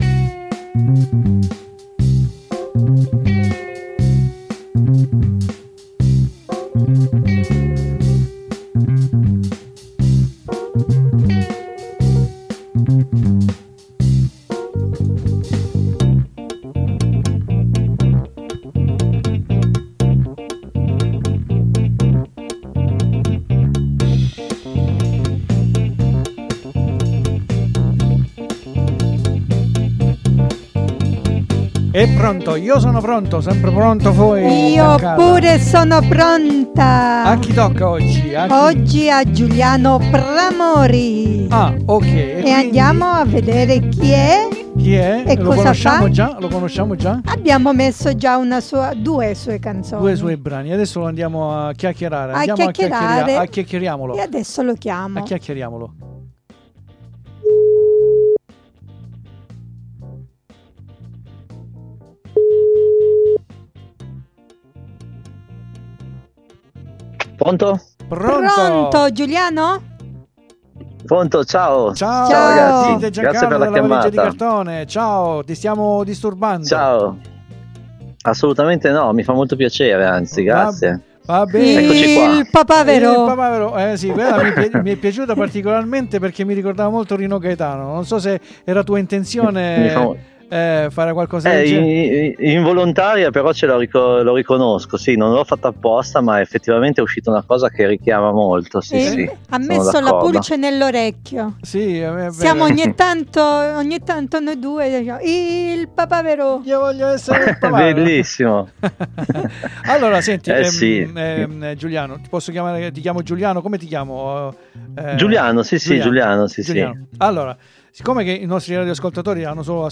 COMUNQUE É UN ARTISTA DA NON SOTTOVALUTARE QUINDI VI INVITO AD ASCOLTARE LA SUA INTERVISTA SUBITO DOPO QUI GIÚ E VI ALLEGO ANCHE IL LINK YOUTUBE DOVE POTRETE SEMPRE TROVARE I SUOI LAVORI!